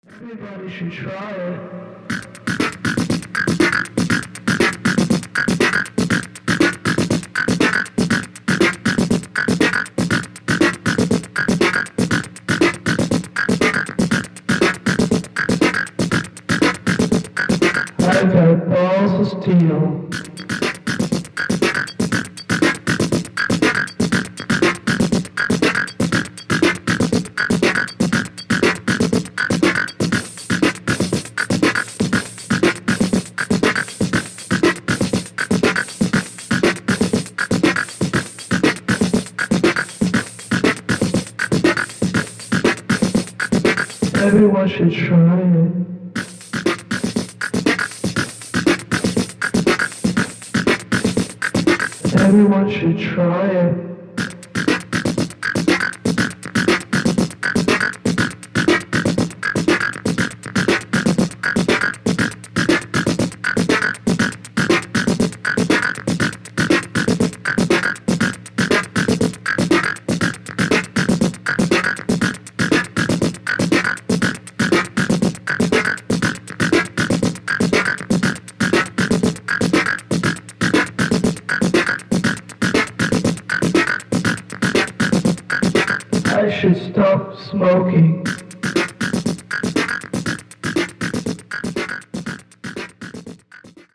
進化したACID,DEEP HOUSE感たまんないですね！！！